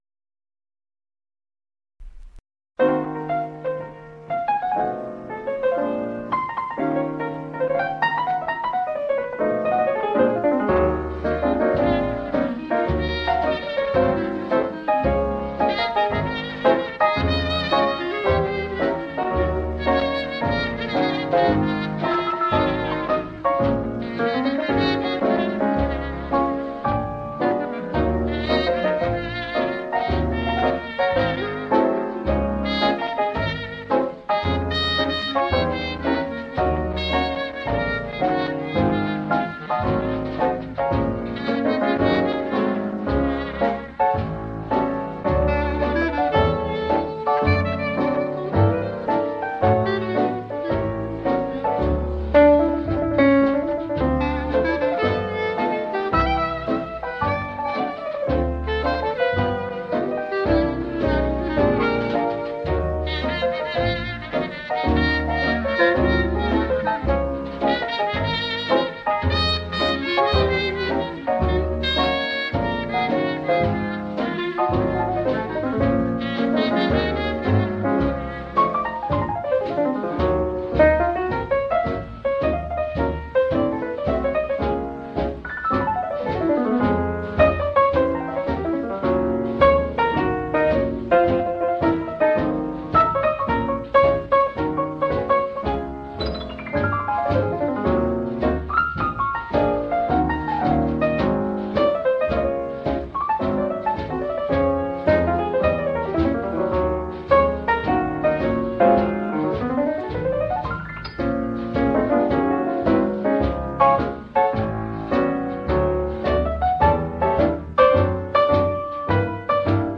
Jazz After Dark spans the gamut, from roots in boogie-woogie, blues, and ragtime through traditional and straight-ahead jazz, soul jazz, bossa nova, and more.